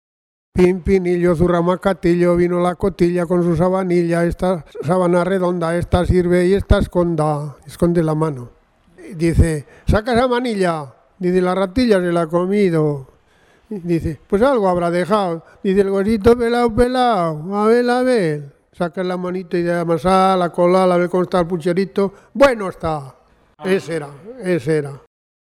Clasificación: Retahílas infantiles
Localidad: Carbonera
Lugar y fecha de recogida: Logroño, 8 de julio de 2004